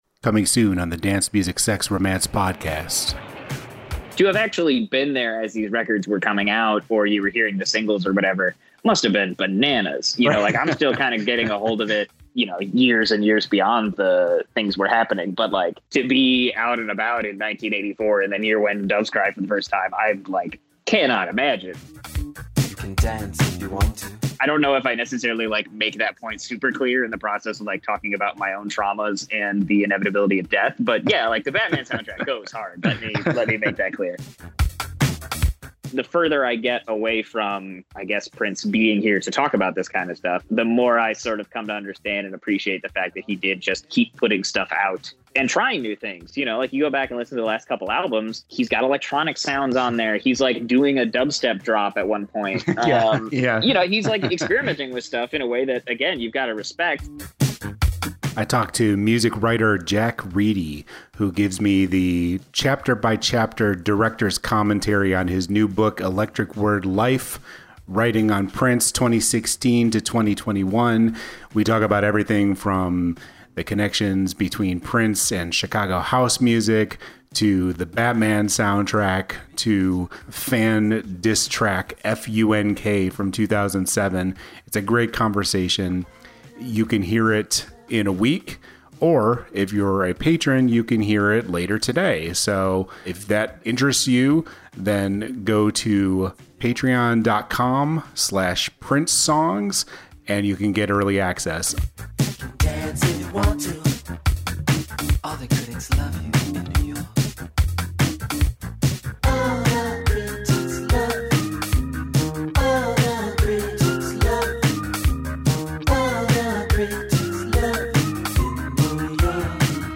A Conversation
electricword-trailer.mp3